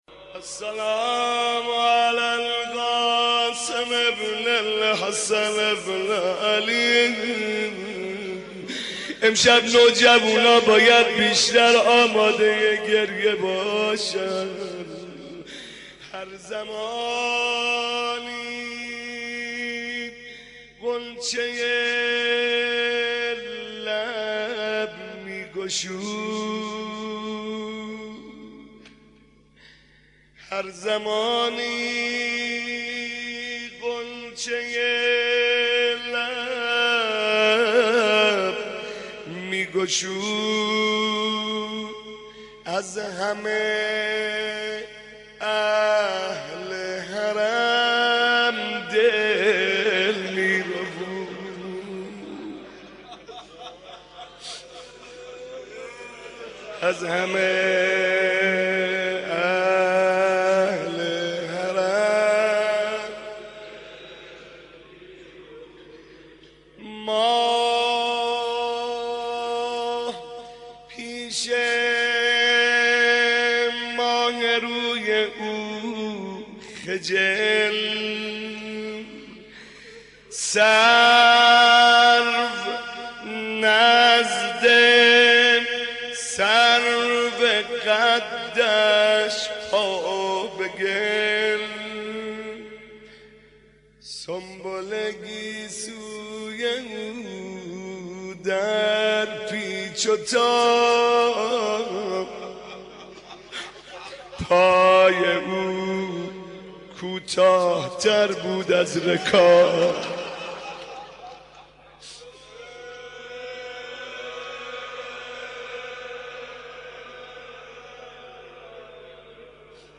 مناسبت : شب ششم محرم